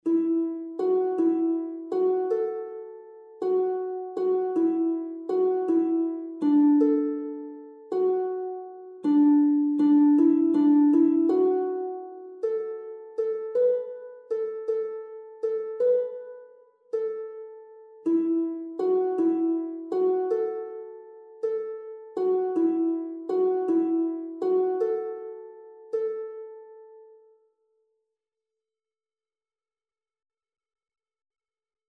Een handenspelletje
dit liedje is pentatonisch